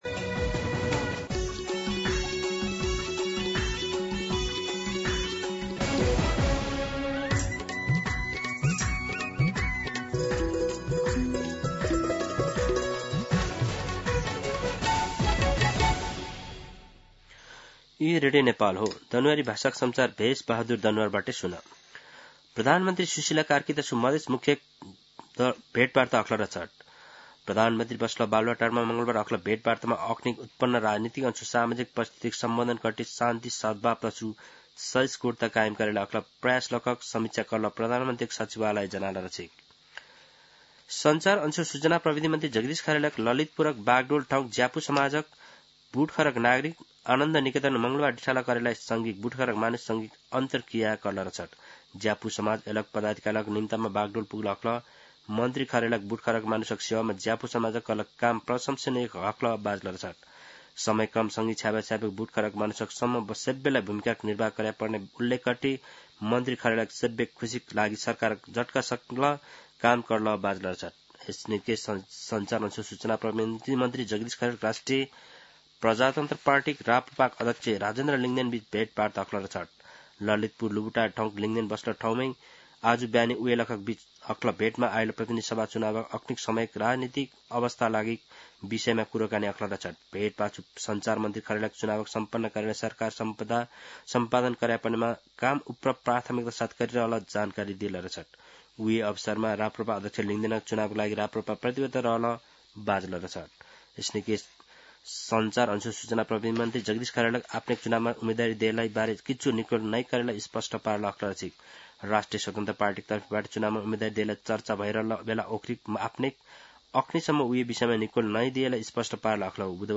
दनुवार भाषामा समाचार : २३ पुष , २०८२
Danuwar-News.mp3